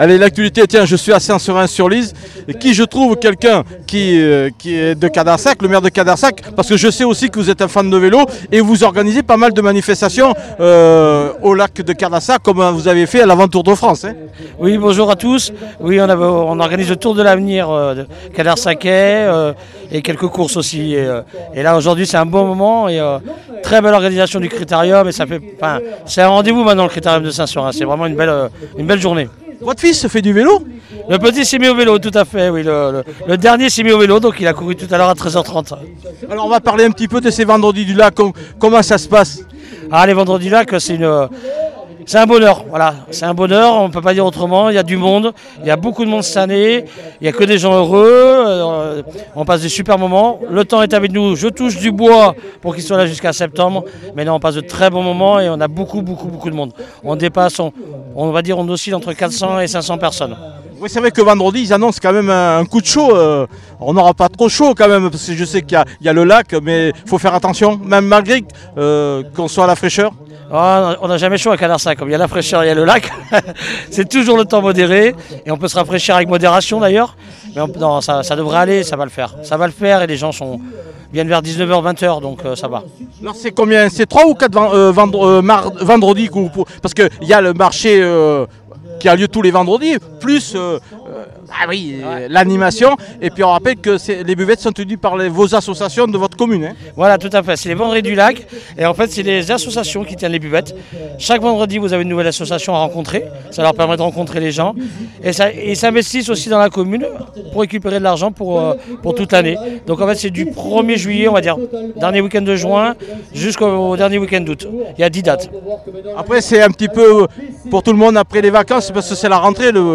Joachim Boisard, maire de Cadarsac, était présent lors du Critérium Cycliste de Saint-Seurin-sur-l'Isle.